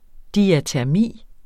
Udtale [ diatæɐ̯ˈmiˀ ]